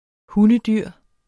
Udtale [ ˈhunəˈdyɐ̯ˀ ]